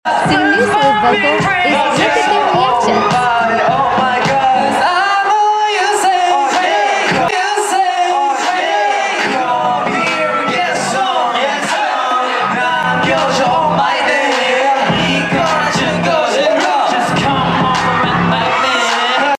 Sunoo's live vocals amazed other sound effects free download